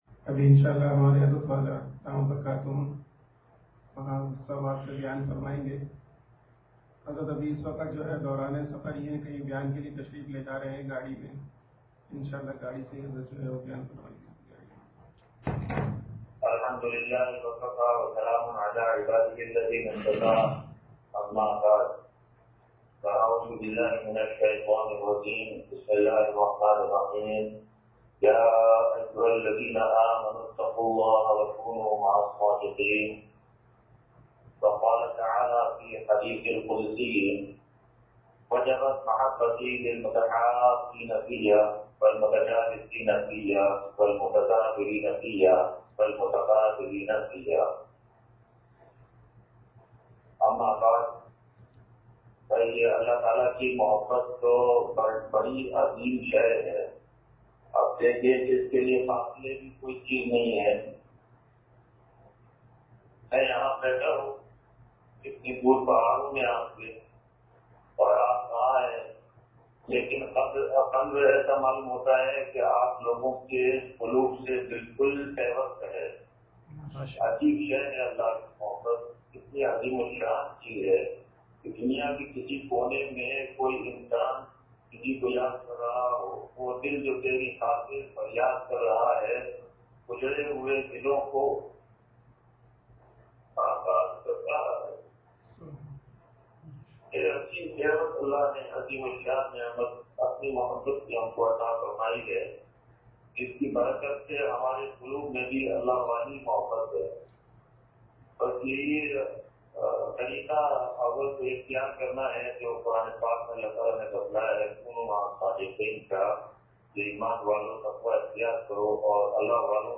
حضرت والا دامت بر کاتہم کا بیان سوات سے – نشر الطیب فی ذکر النبی الحبیب صلی اللہ علیہ وسلم – اتوار